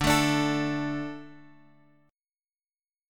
D5 chord {x 5 x 2 3 5} chord
D-5th-D-x,5,x,2,3,5.m4a